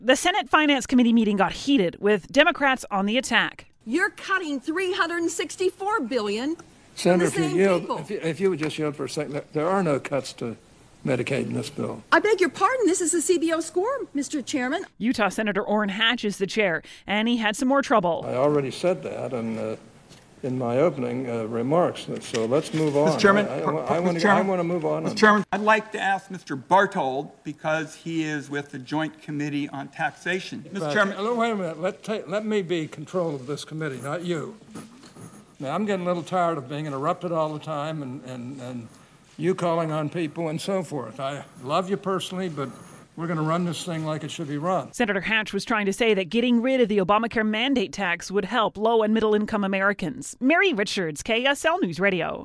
A Senate Finance Committee hearing got heated Wednesday as the committee's chair, Sen. Orrin Harch, defended the decision to include in the tax bill a repeal of the Obamacare requirement for Americans to get health insurance.